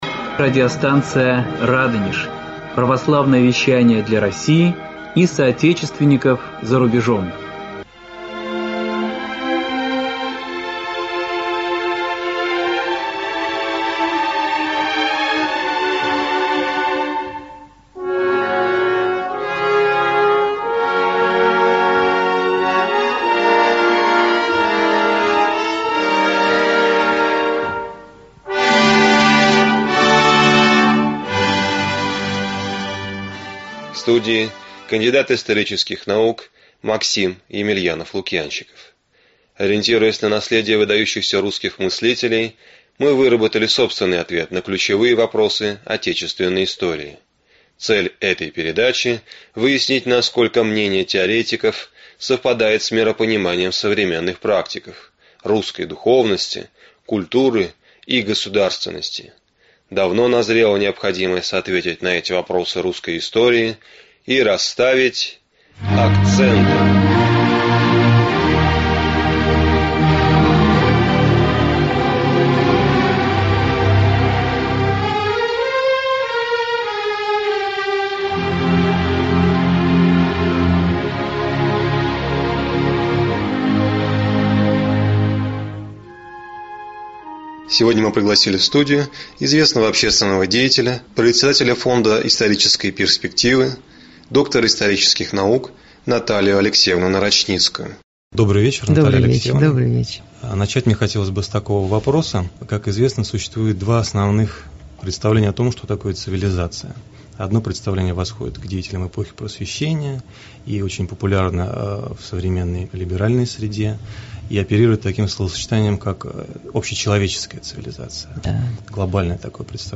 Аудиокнига Акценты. История как политика | Библиотека аудиокниг
История как политика Автор Наталия Нарочницкая Читает аудиокнигу Наталия Нарочницкая.